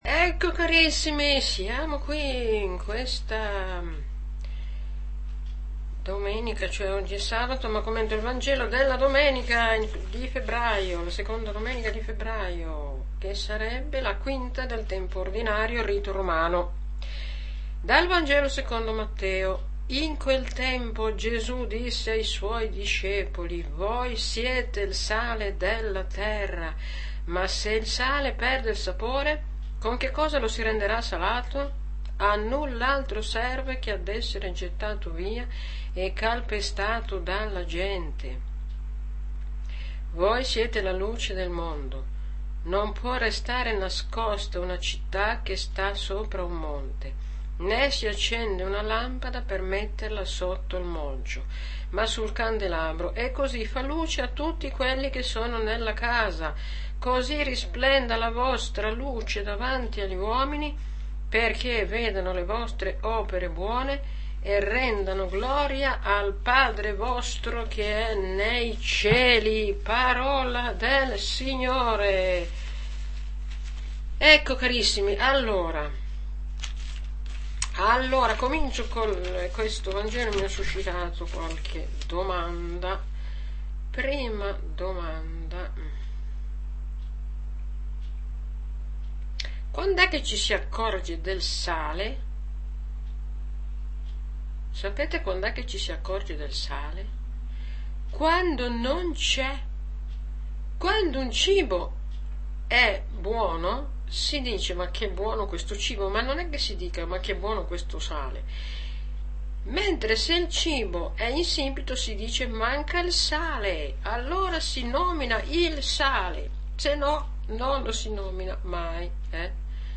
AUDIO Audio commento alla liturgia - Mt 5,13-16